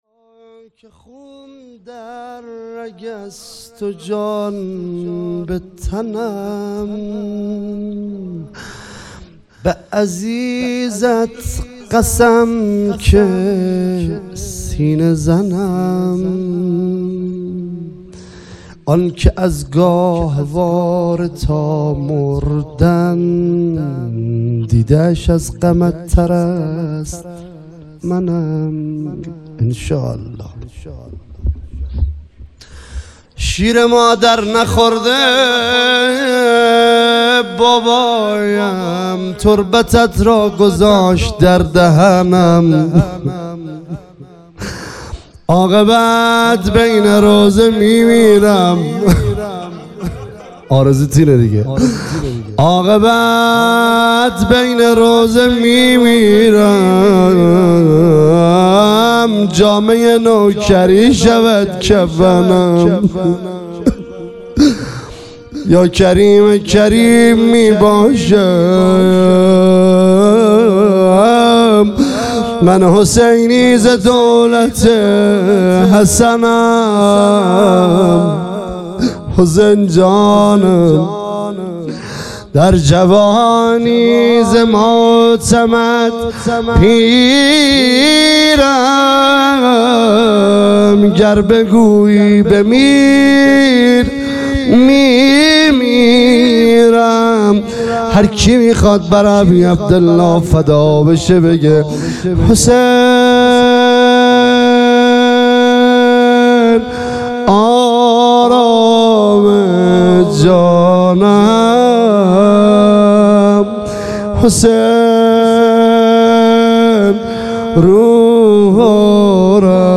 مداحان